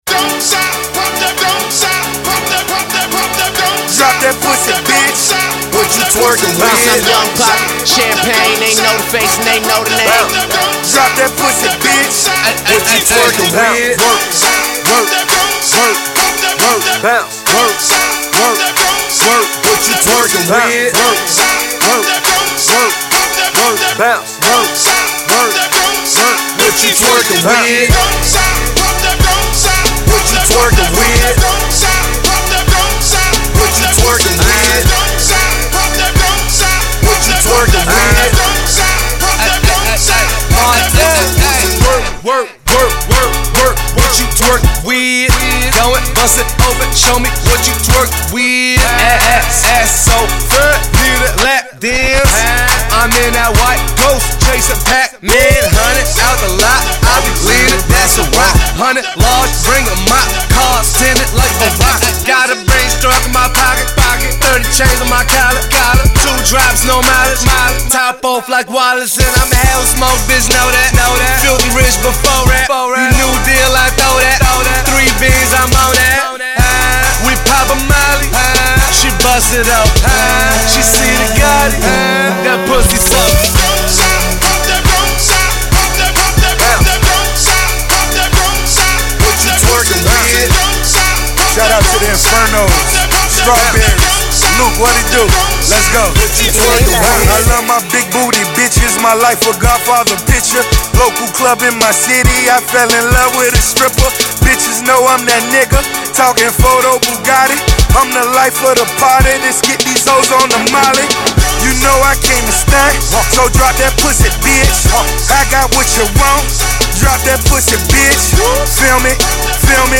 club single